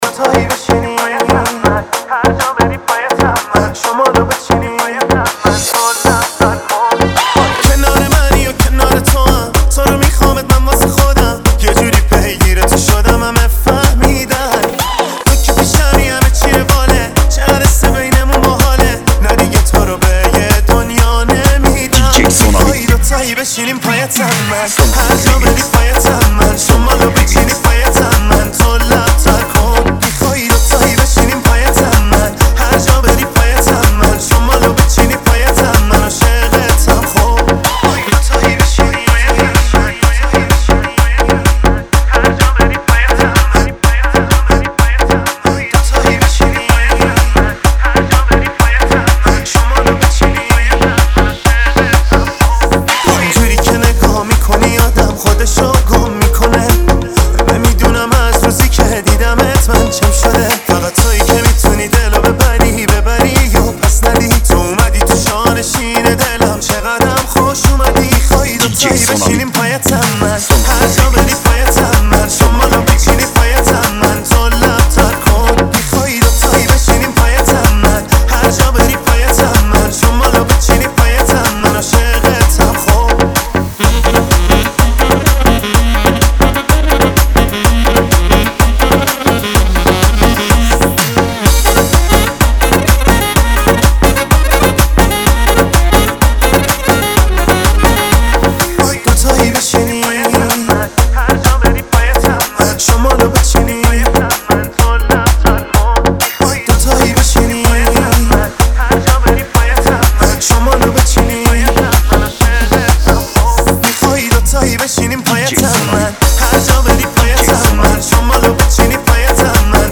ریمیکس شاد ایرانی
ریمیکس شاد رقصی